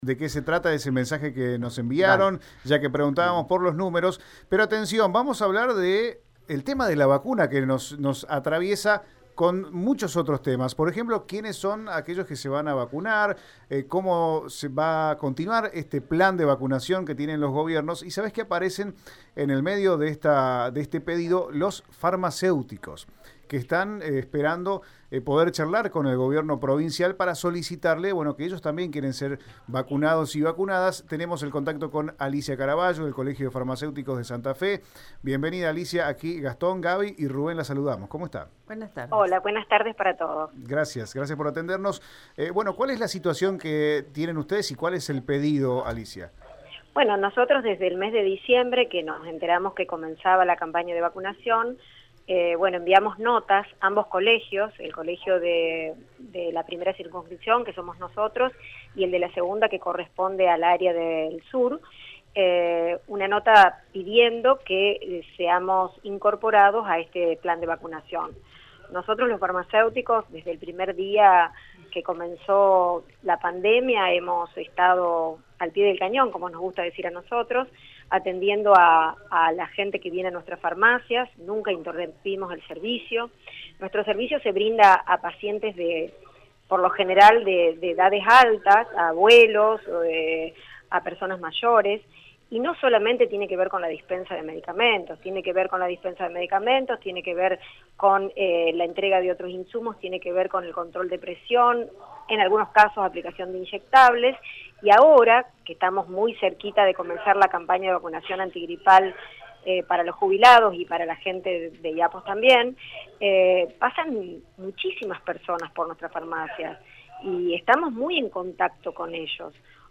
En dialogo con Radio EME